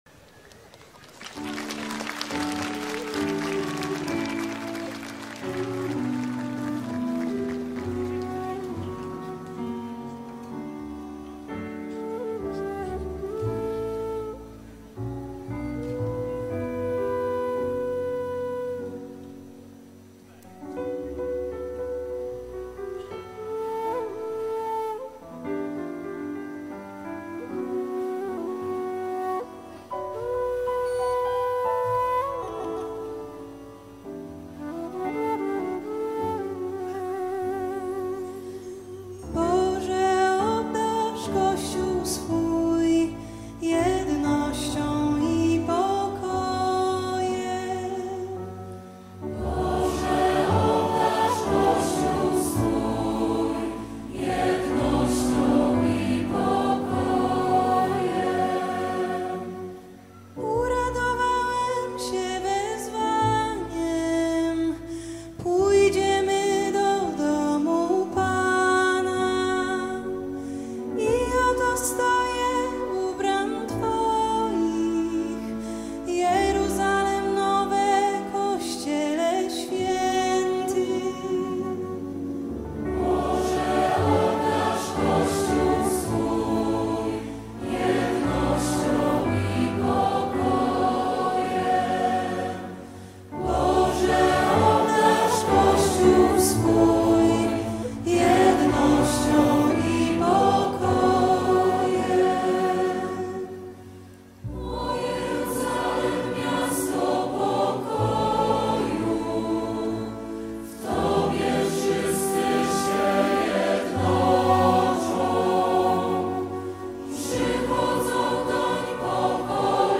Rzeszów (Live)